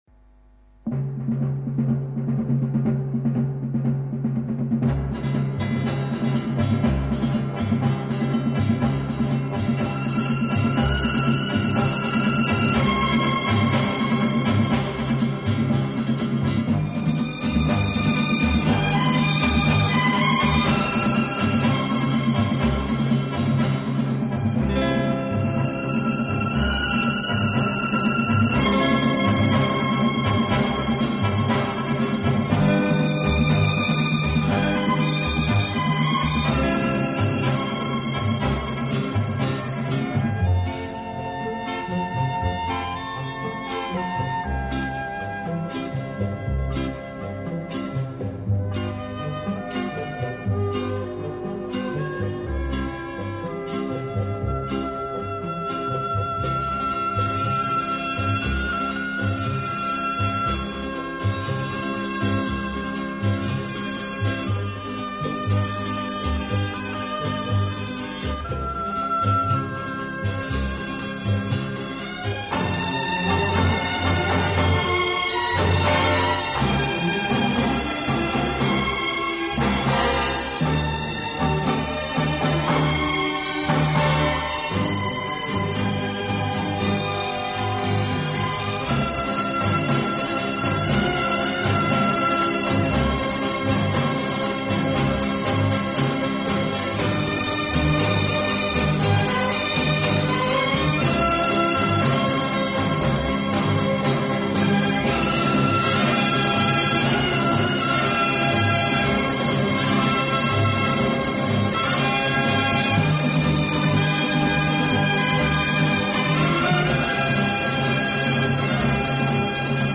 【顶级轻音乐】
Genre: Instrumental
舒展，旋律优美、动听，音响华丽丰满。